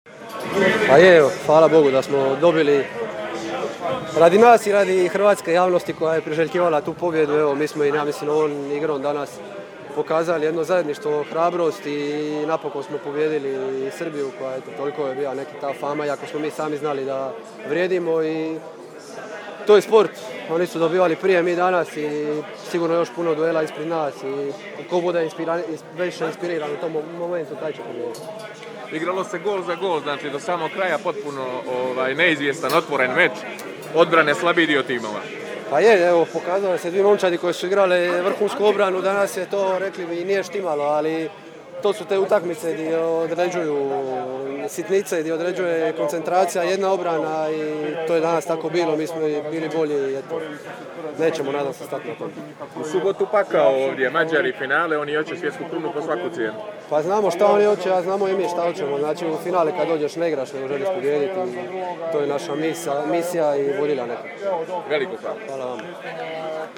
IZJAVE POBJEDNIKA: